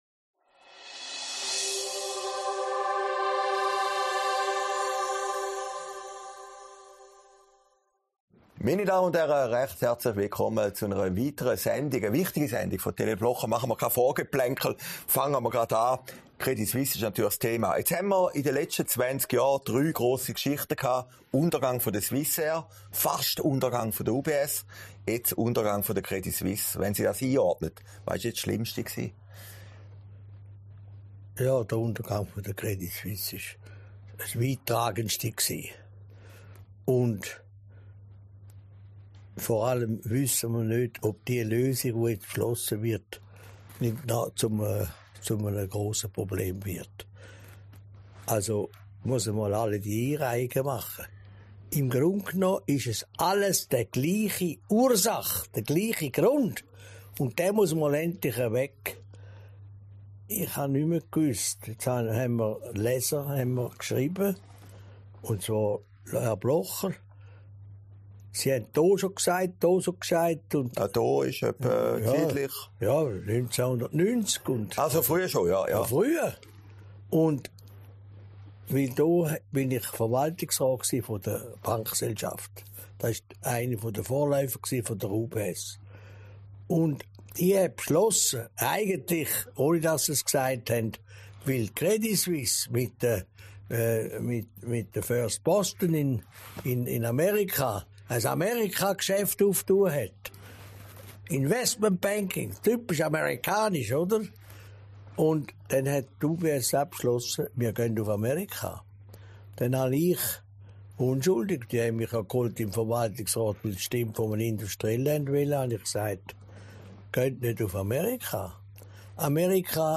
Sendung vom 24. März 2023, aufgezeichnet in Herrliberg